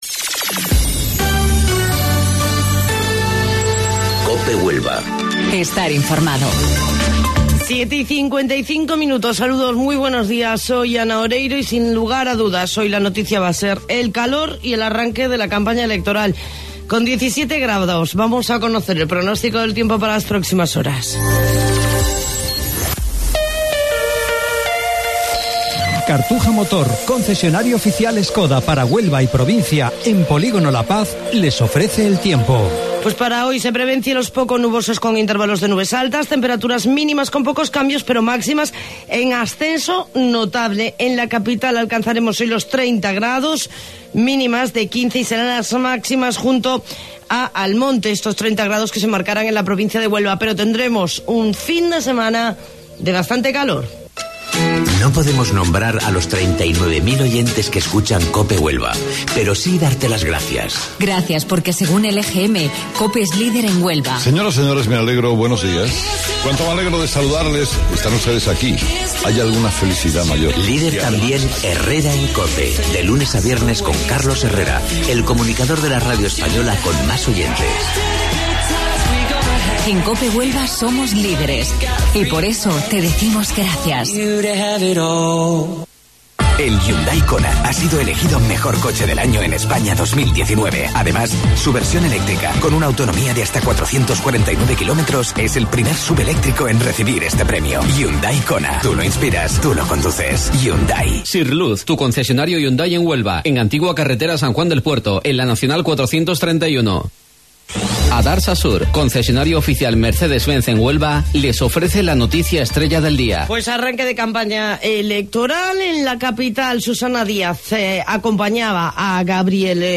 AUDIO: Informativo Local 07:55 del 10 de Mayo